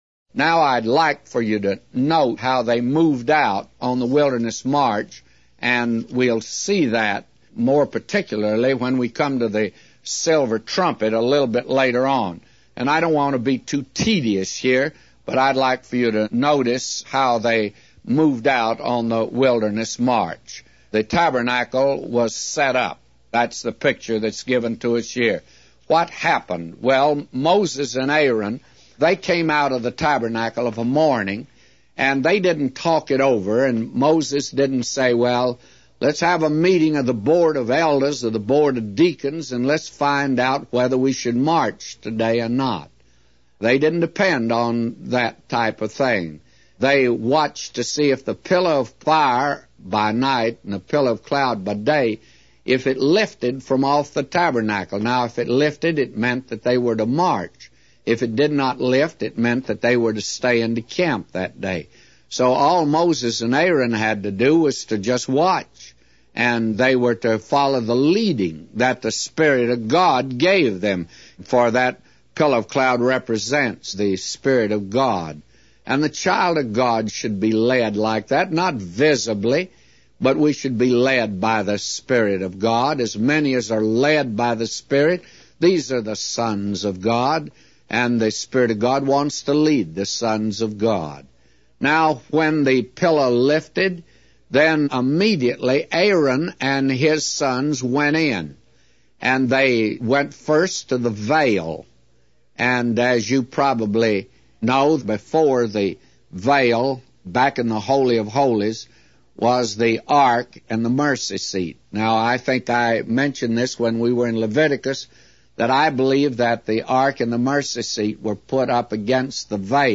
Evening Bible Reading - Mark 3